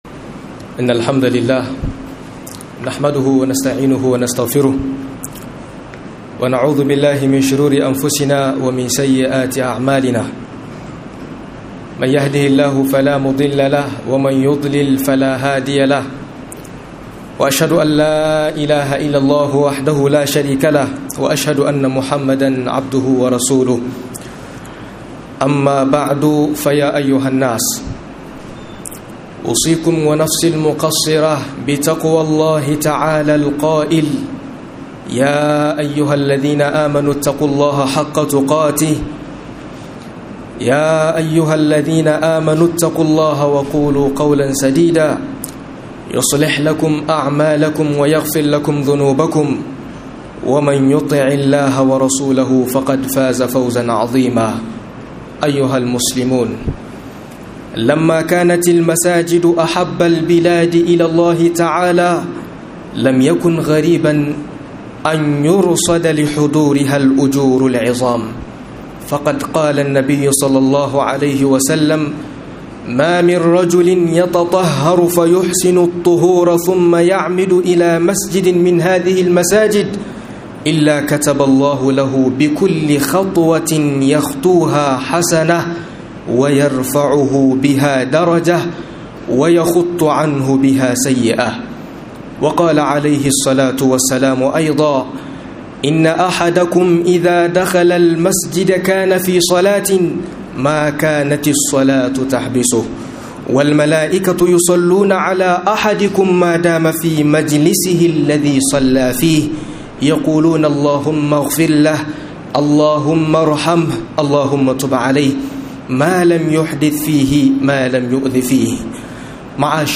Ladubban Halartar Masallaci - MUHADARA